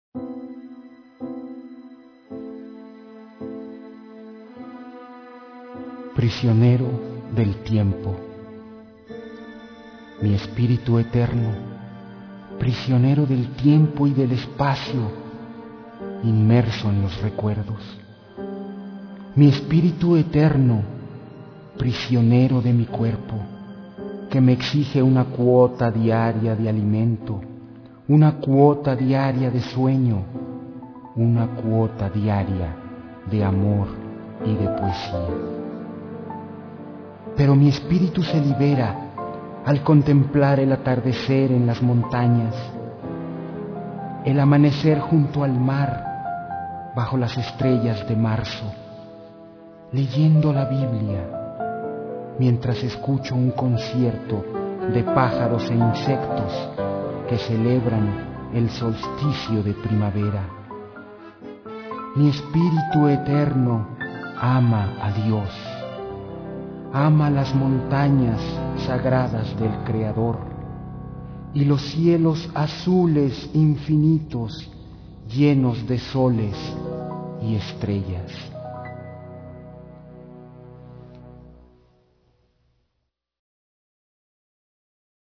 P O E M A S
C A L I D A D     M O N O